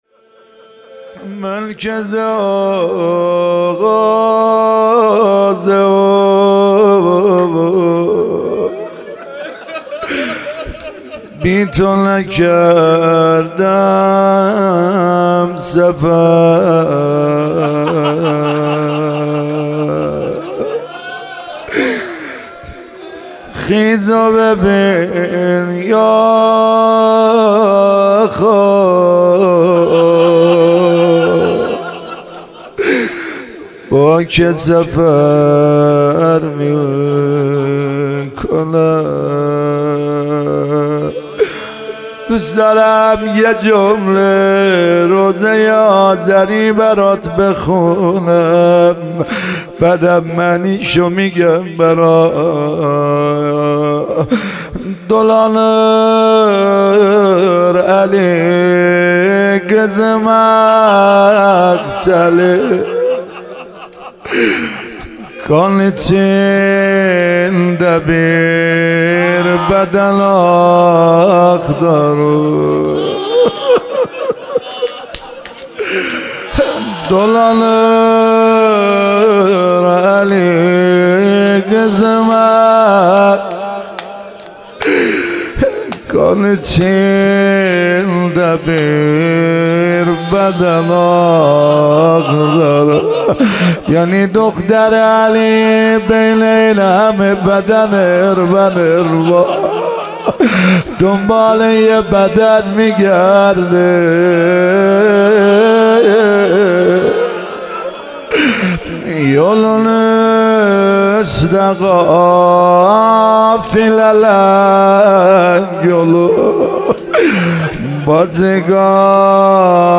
مناسبت : شب بیست و چهارم رمضان
قالب : روضه